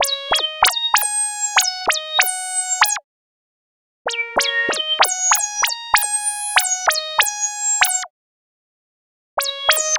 Night Rider - Bubble Lead.wav